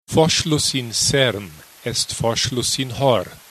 Language quiz
Someone once said that that recording sounded like Brasilian Portuguese.